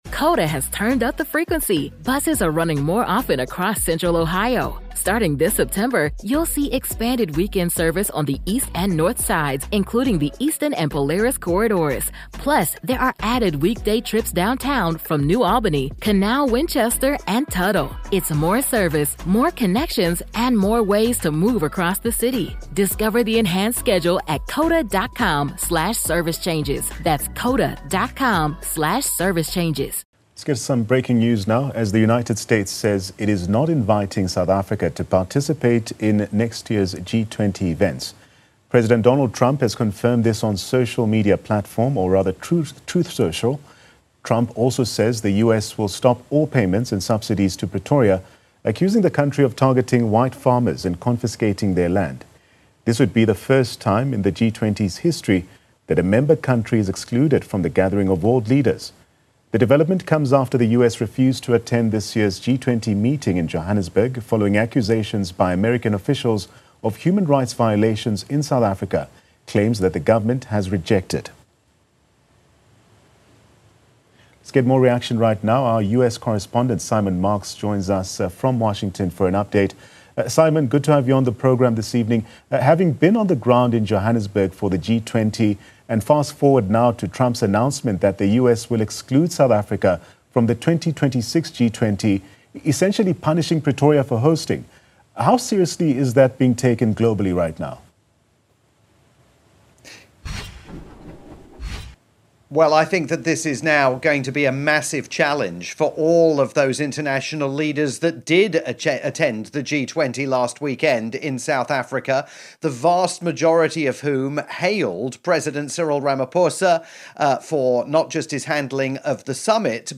breaking news update for Newzroom Afrika, South Africa's top-rated TV news channel.